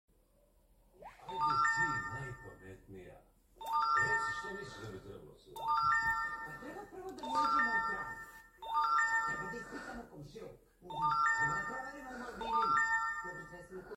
pixie dust sound be like... sound effects free download